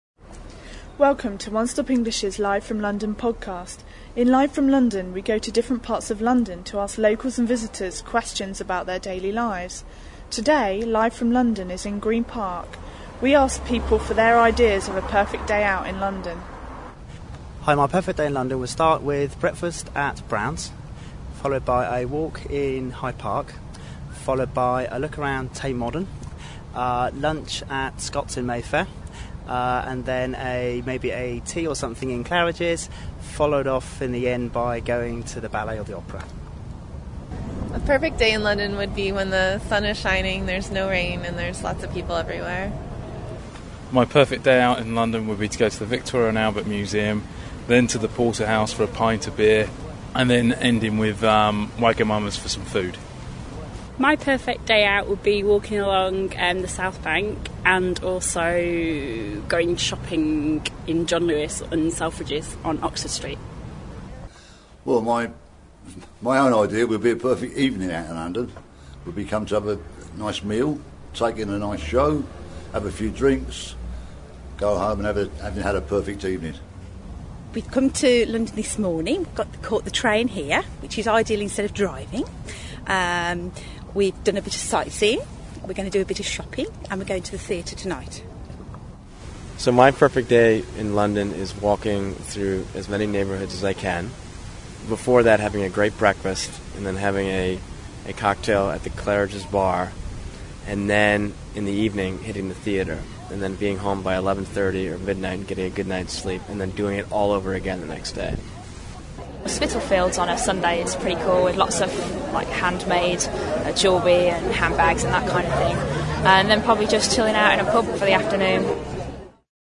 We went to Green Park and asked people what they thought was a perfect day out in London.